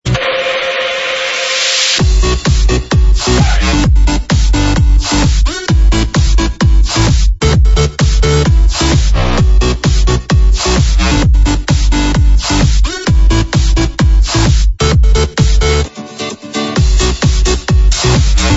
Стиль: Electro House